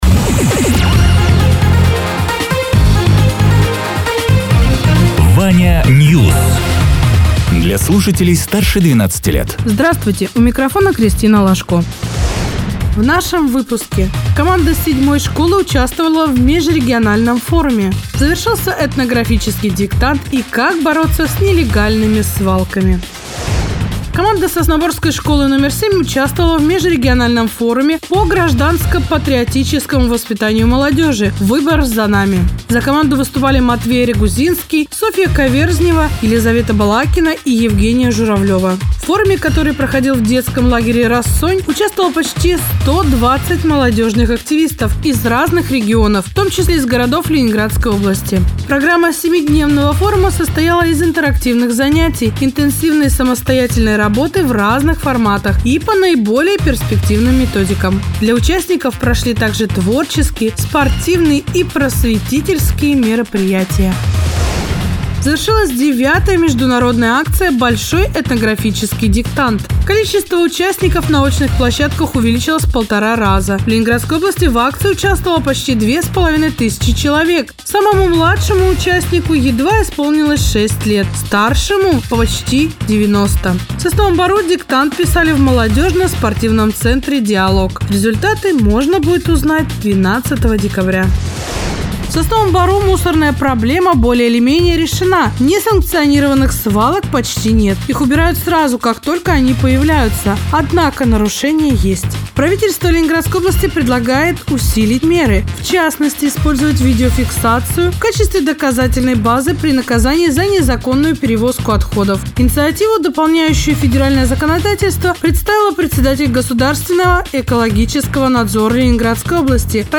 Радио ТЕРА 19.11.2024_10.00_Новости_Соснового_Бора